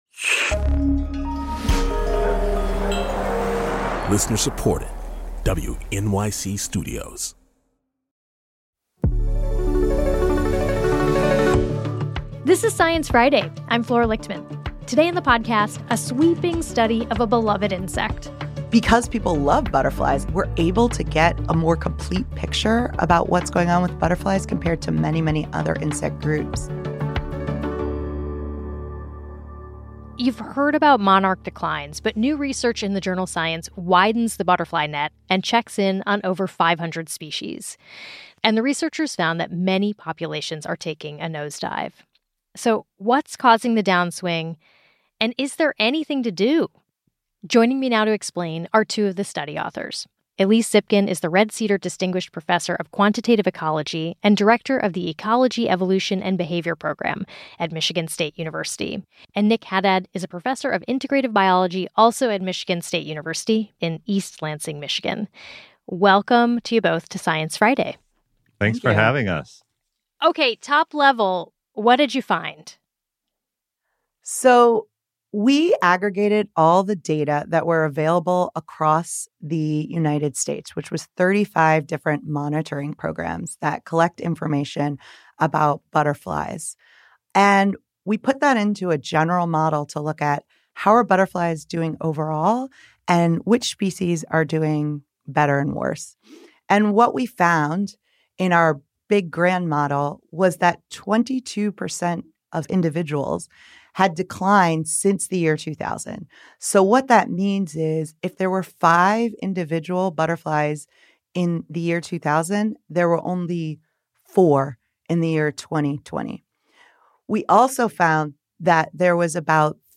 talks with two of the study authors